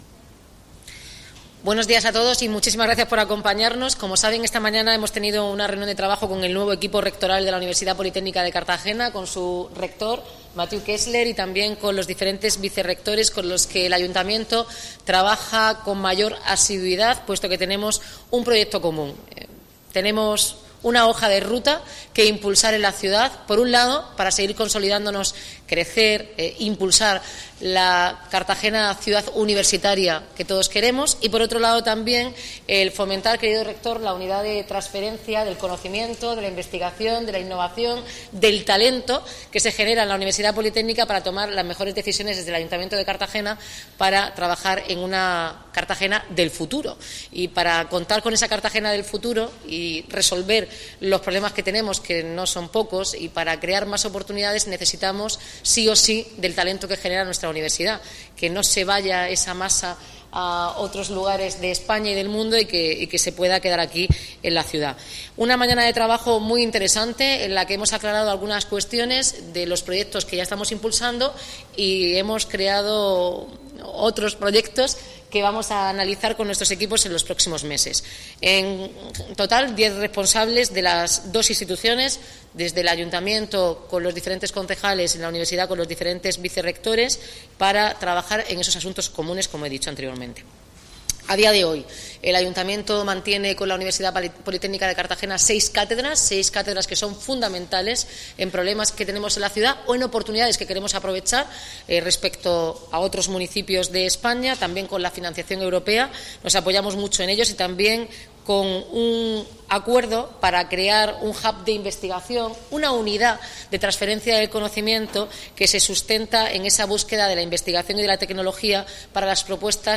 Enlace a Declaraciones de la alcaldesa, Noelia Arroyo, y el rector de la UPCT, Mathieu Kessler